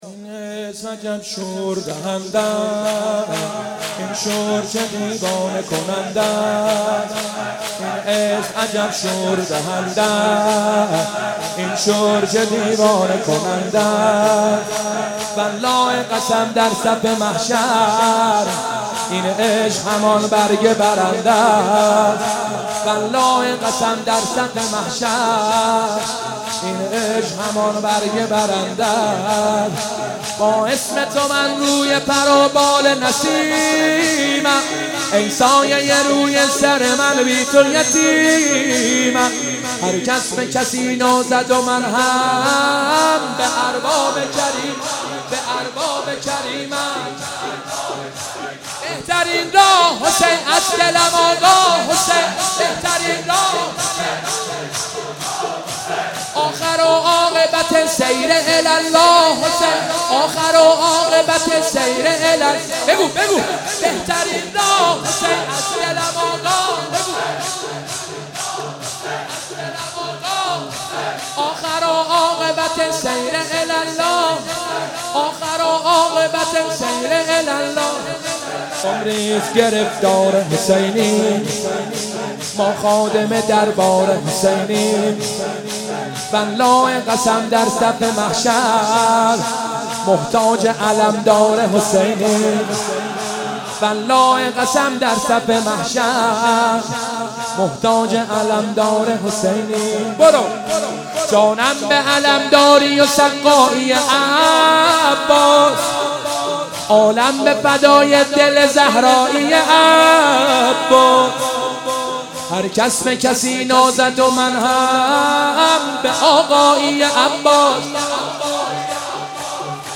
شب تاسوعا محرم95/هیئت خادم الرضا (ع) قم
شور/این اسم عجب شور